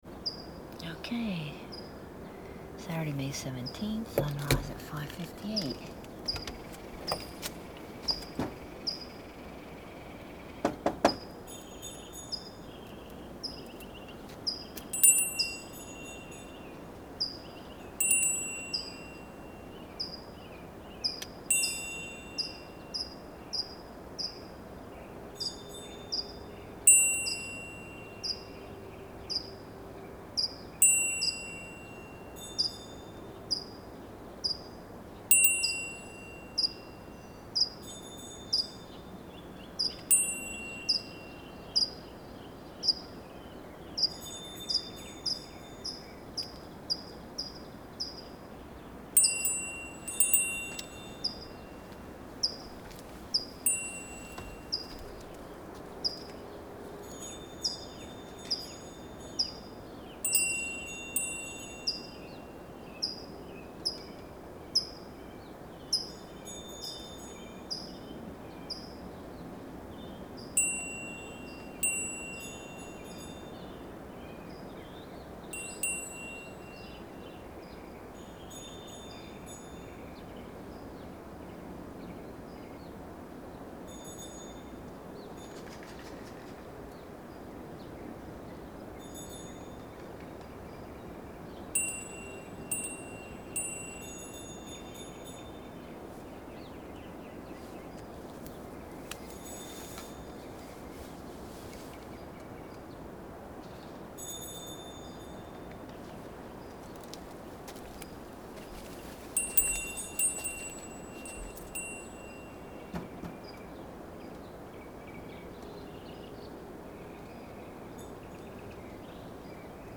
5/17 AM Trio for Bells and Bird
It is Saturday morning so I stayed at home for the sunrise and recorded
It was a nice little trio with the two of us ringing bells and that one very insistent bird.